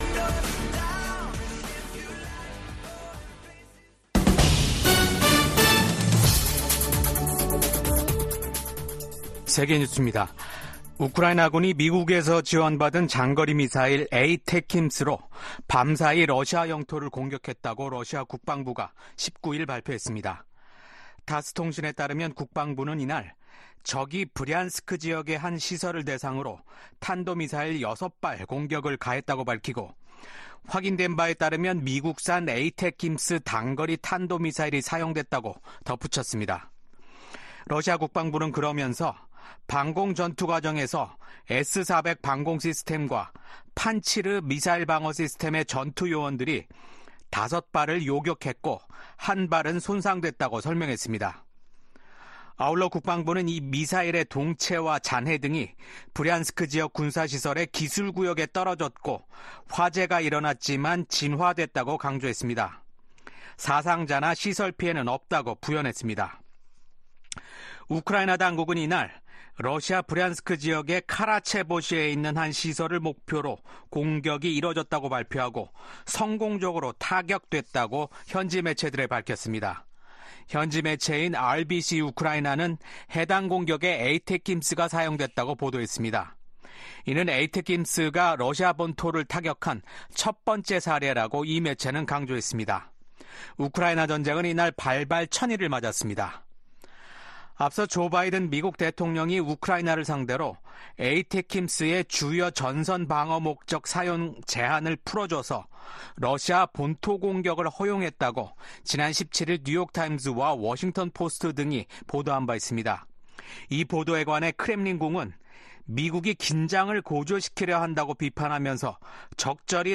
VOA 한국어 아침 뉴스 프로그램 '워싱턴 뉴스 광장'입니다. 미국은 러시아가 북한군을 우크라이나 전쟁에 투입해 분쟁을 고조시키고 있다며, 북한군의 추가 파병을 차단하기 위해 중국과 직접 소통하고 있다면서, 단호한 대응 의지를 확인했습니다. 우크라이나 전쟁 발발 1천일을 맞아 열린 유엔 안보리 회의에서 북한군의 러시아 파병과 두 나라 간 군사 협력에 대한 강한 비판이 쏟아졌습니다.